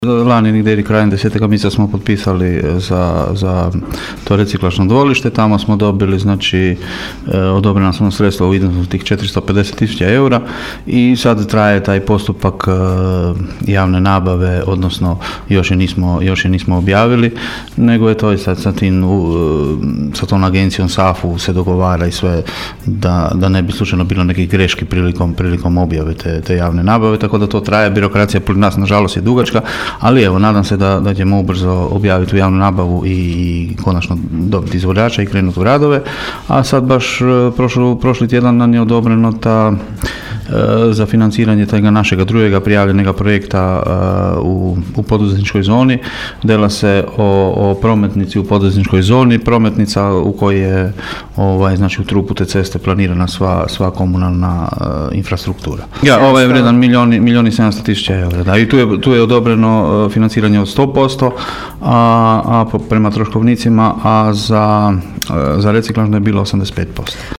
ton – Dean Močinić), rekao je općinski načelnik Dean Močinić.